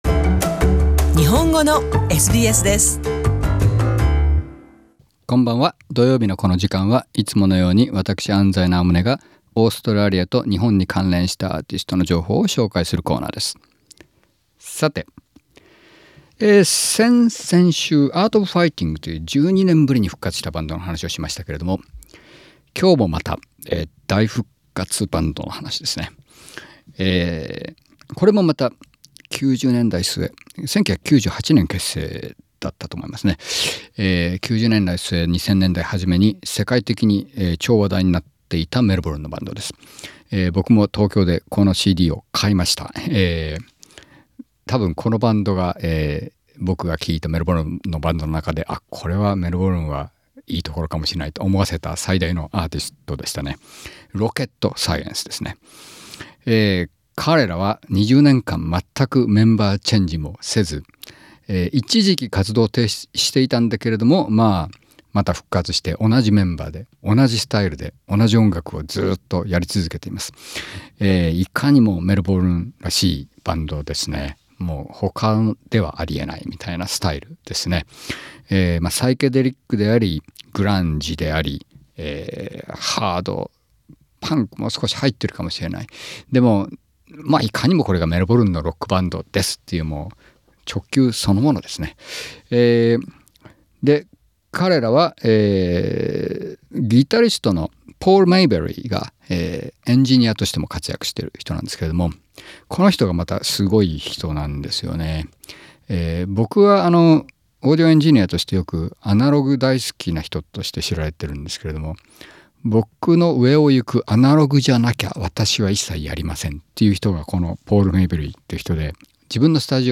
20年間メンバーチェンジなしでやってきた典型的なメルボルンバンドで、デジタル時代にも関わらず1960年代のアナログの音にこだわっている。